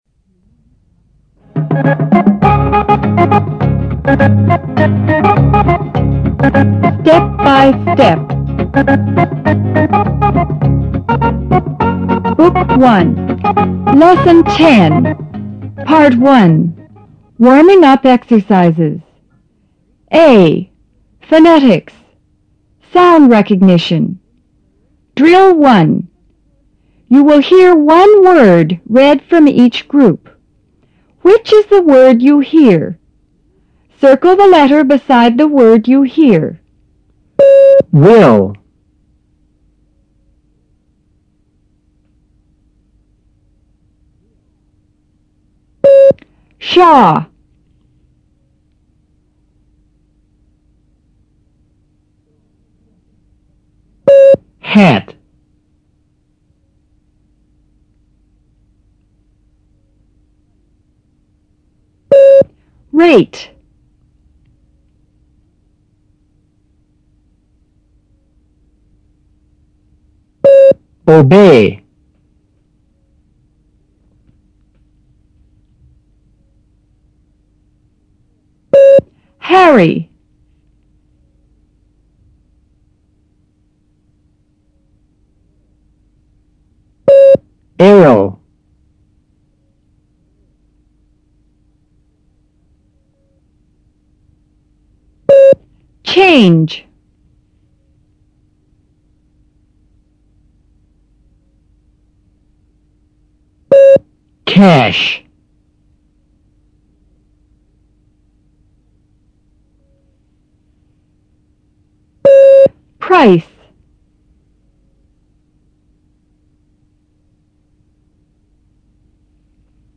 A．Phonetics: Sound Recognition
Directions: You will hear one word read from each group.